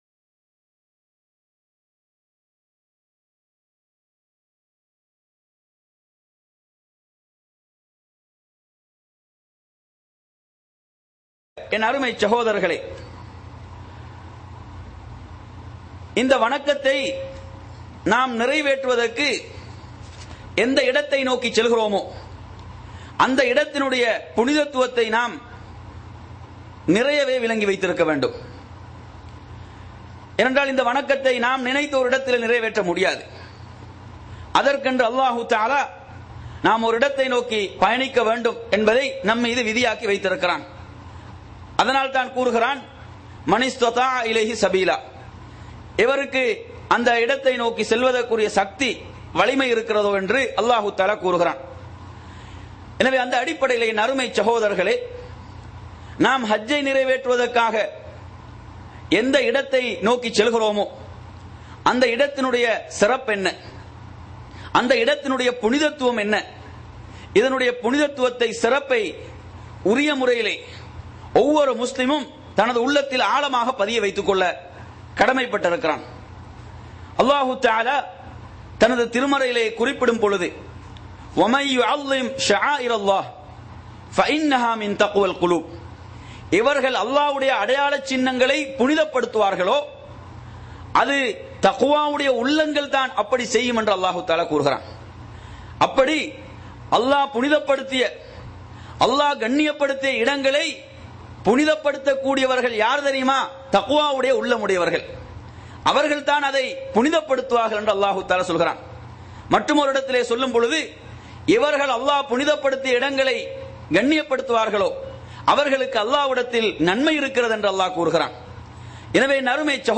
இடம் : ஐ.டி.ஜி.சி, தம்மாம், சவூதி அரேபியா